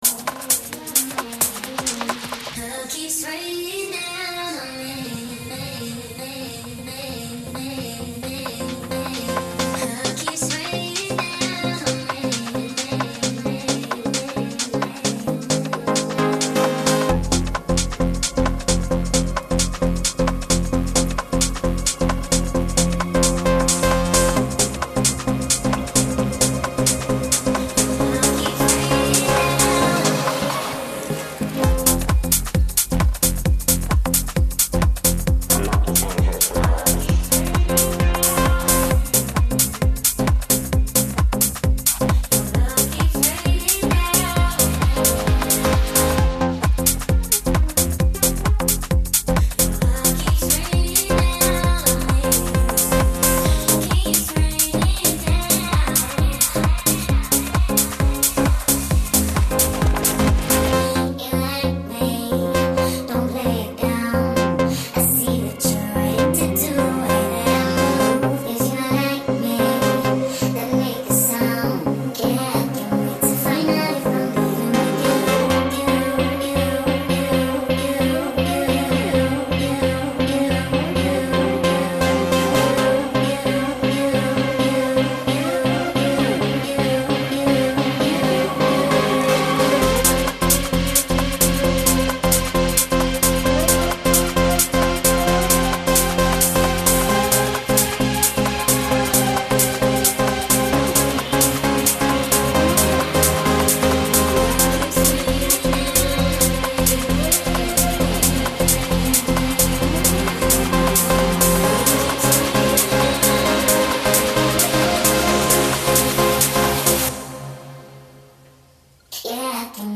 Off the tail end of the epic Raglan Arts Weekend we drag ourselves into the studio for what ends up being a deeply energising medley of dance music.